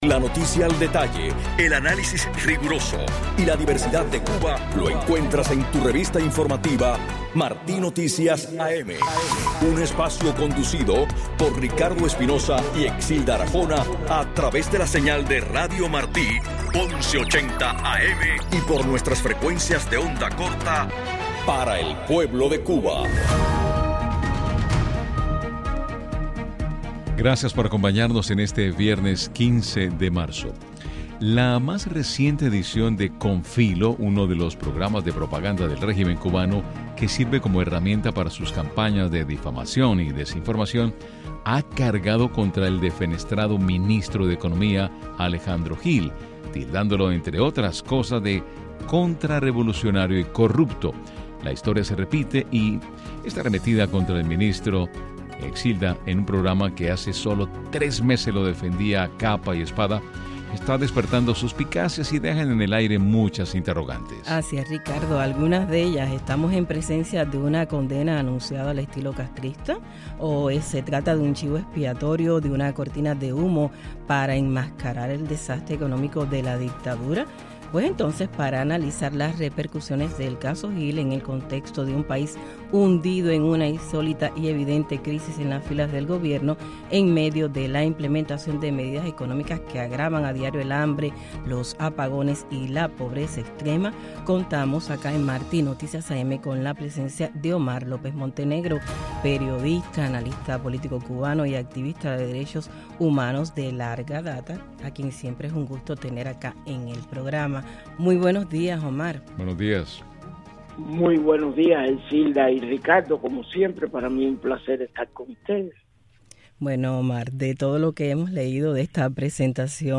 Declaraciones del analista político cubano